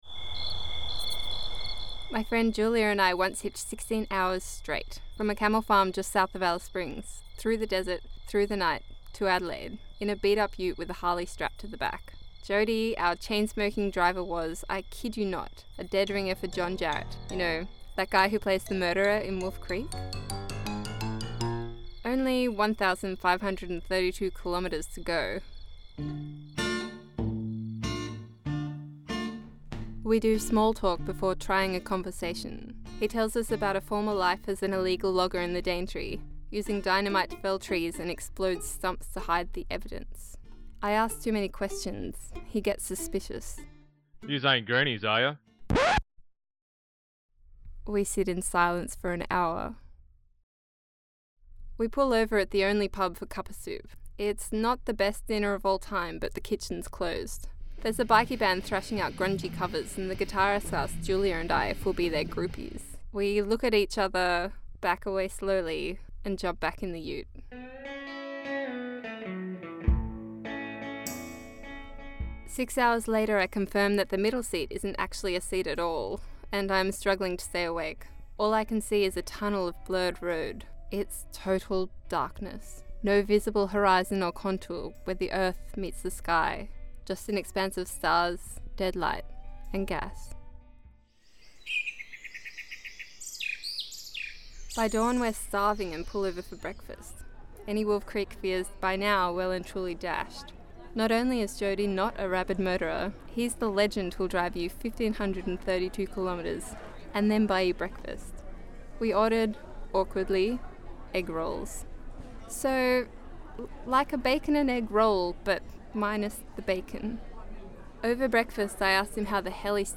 This is a radio version of ‘Hitch’